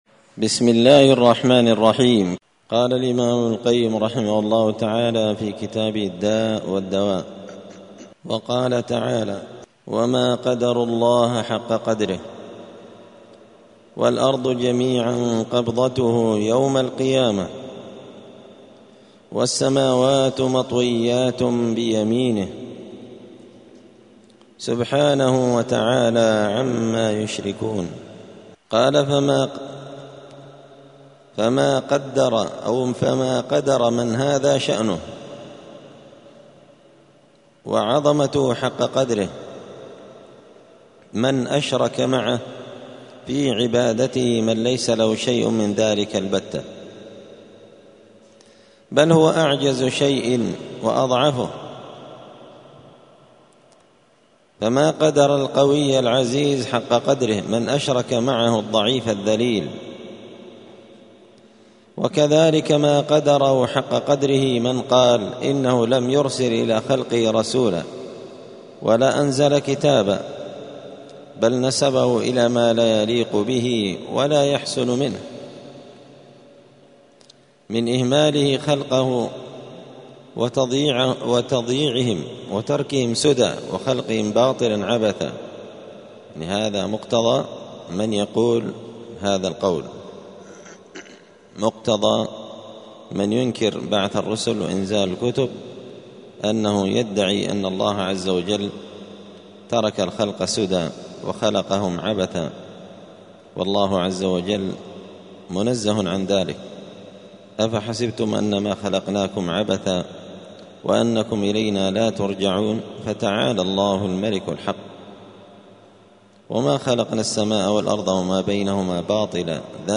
*الدرس الثالث والستون (63) تابع لفصل سوء الظن بالله*